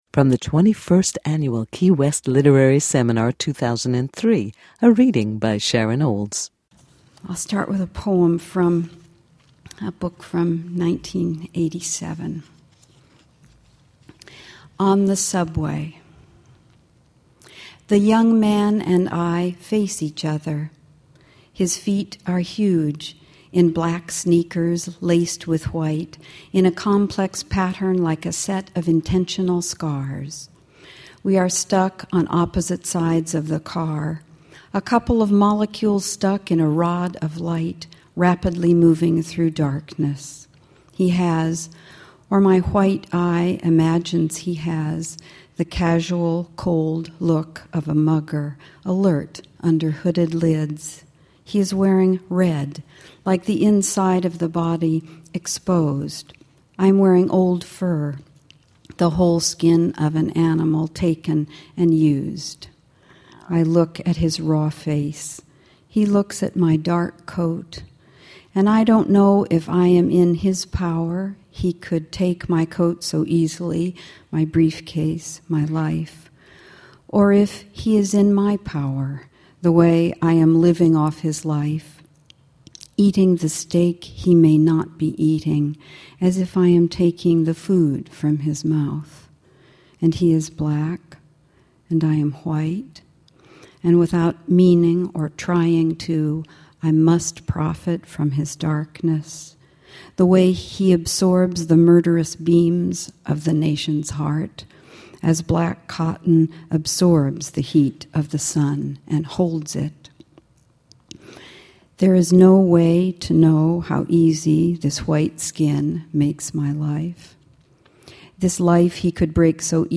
TWENTY-FIRST ANNUALKey West Literary Seminar the beautiful changespoetry 2003 Sharon Olds